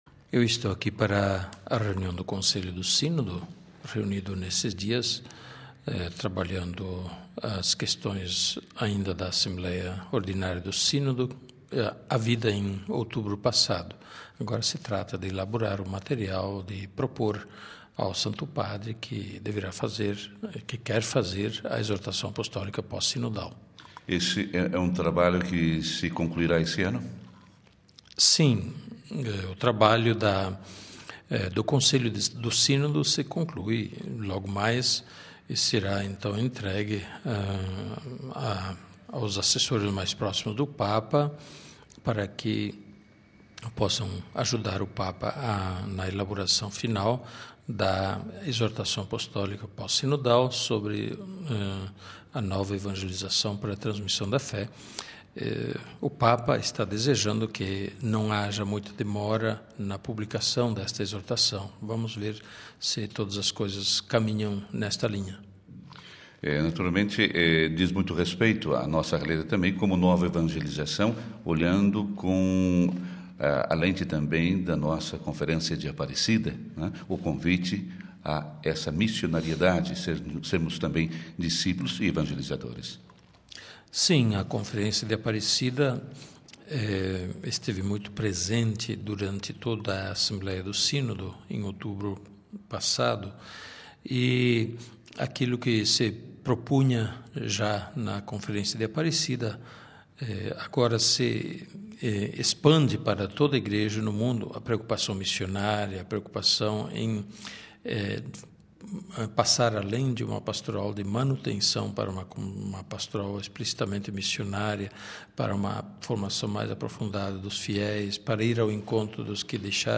Cidade do Vaticano (RV) – Presente nesta quinta-feira em Roma, o Arcebispo de São Paulo, Cardeal Odilo Scherer, que conversou com a Rádio Vaticano sobre o motivo de sua passagem por Roma.